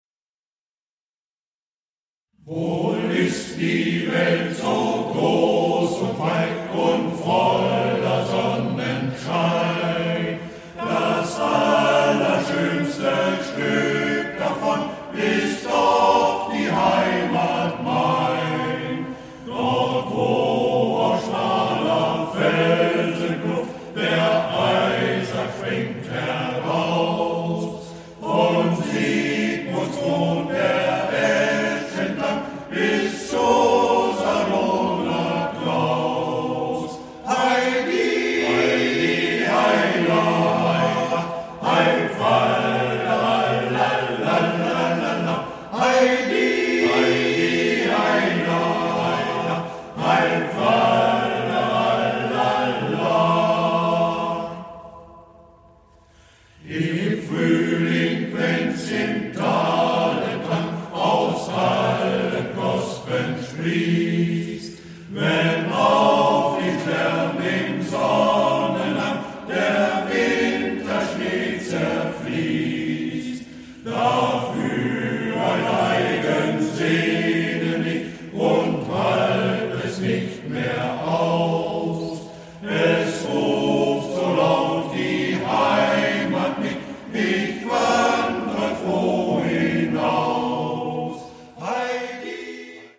– MGV Concordia e.V. Salzderhelden
Aufnahmen: Tonstudio Würfel, Uslar
Aufnahmeort: St. Jacobi-Kirche Salzderhelden